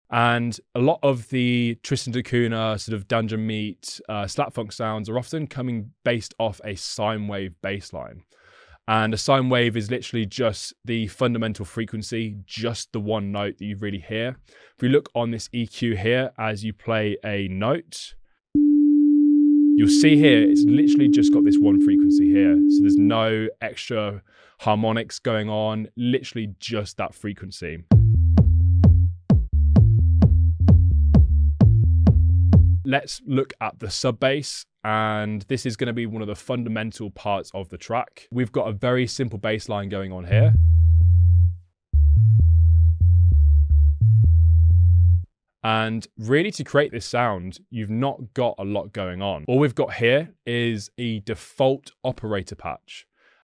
SlapFunk Style Sub Bass. The deep sound effects free download
The deep basslines in Dungeon Meat and SlapFunk records, often come from a simple sine wave.